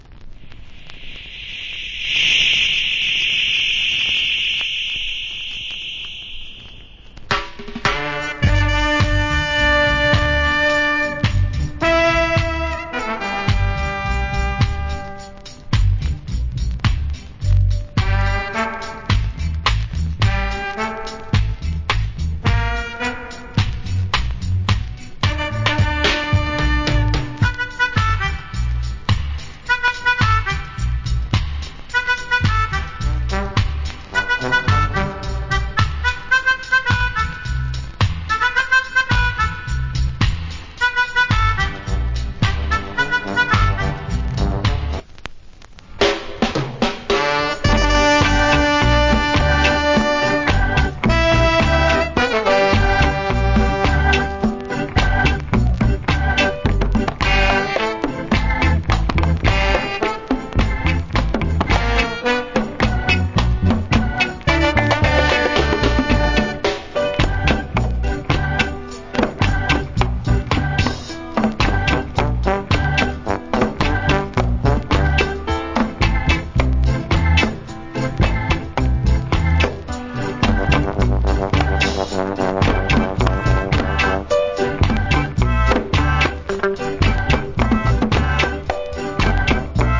Nice Reggae Inst,